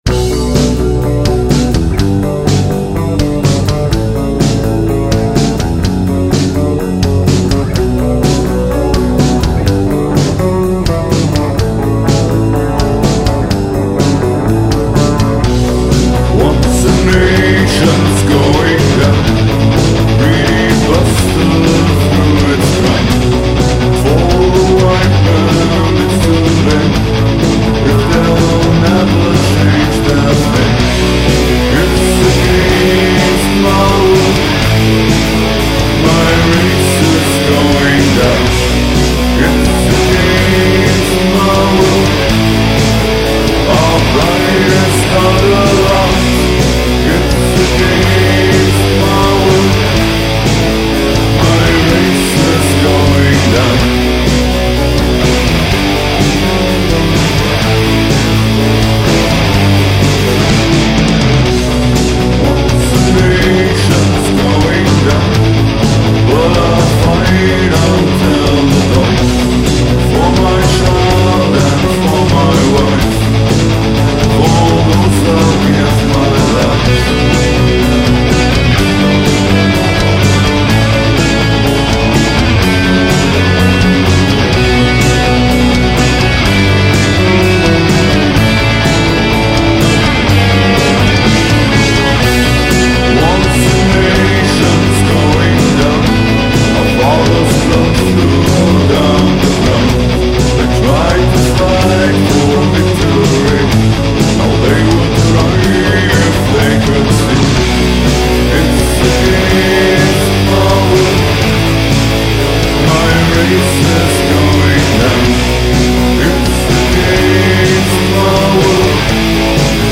intelligente Songs gepaart mit Härte und Melodie